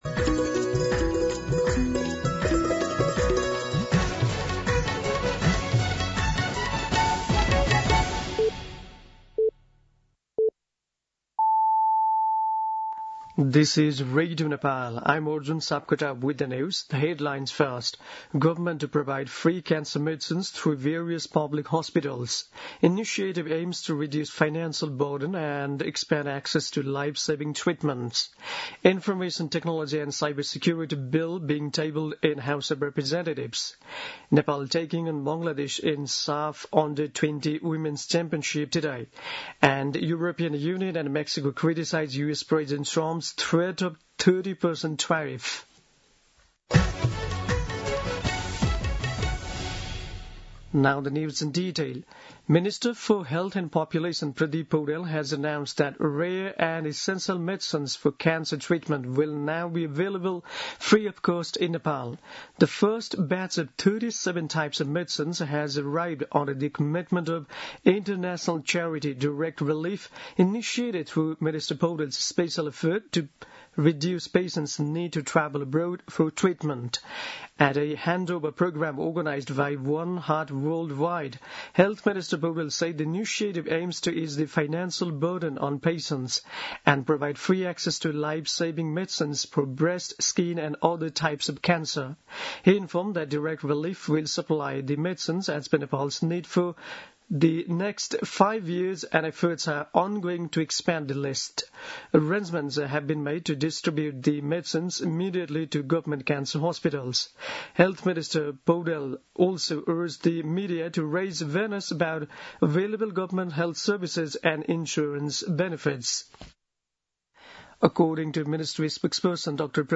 दिउँसो २ बजेको अङ्ग्रेजी समाचार : २९ असार , २०८२
2-pm-English-News-3-29.mp3